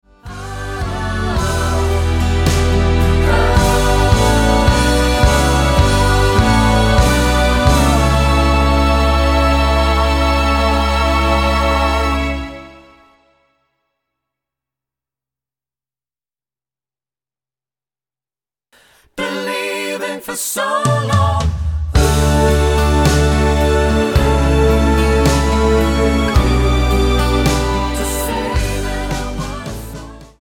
Tonart:D mit Chor